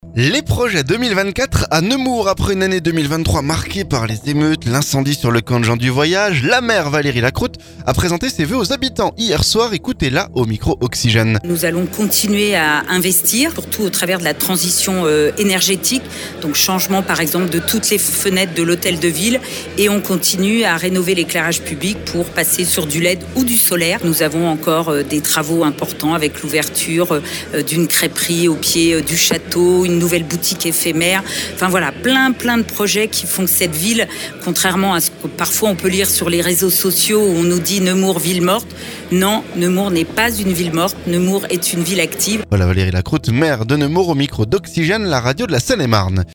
Après une année 2023 marquée par les émeutes et l'incendie sur le camp de gens du voyage, la maire, Valérie Lacroute, a présenté ses vœux aux habitants jeudi soir... Ecoutez là au micro Oxygène.